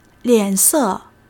lian3-se4.mp3